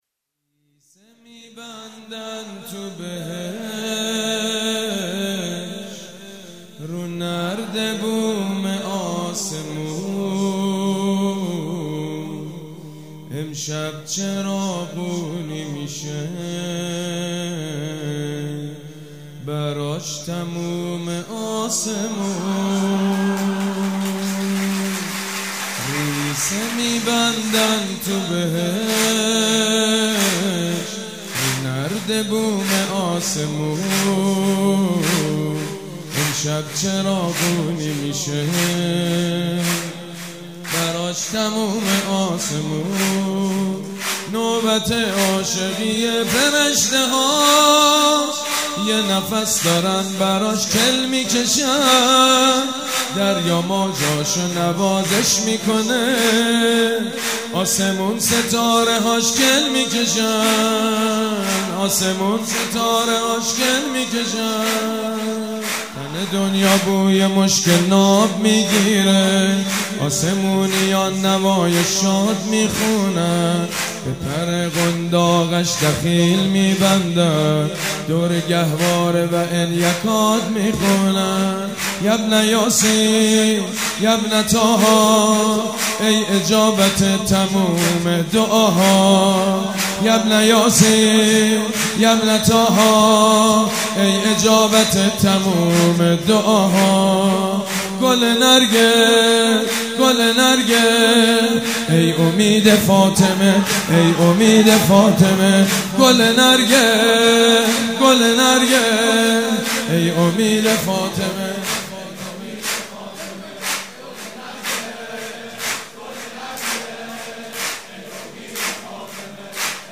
جشن میلاد امام زمان(عج)
سرود
مداح
حاج سید مجید بنی فاطمه
جشن نیمه شعبان